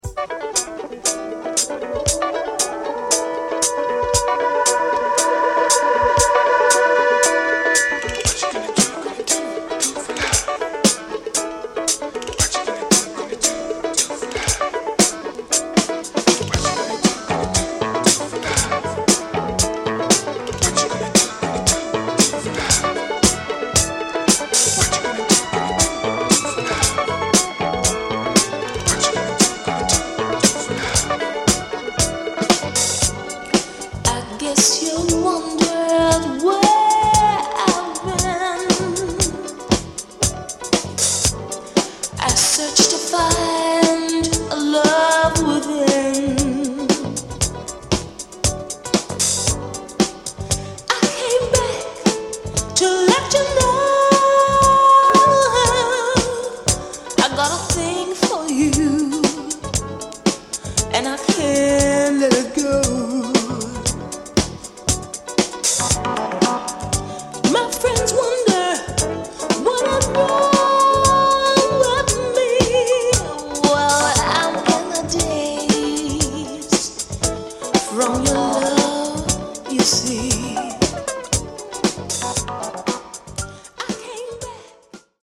Biig underground, boogie holy grailer!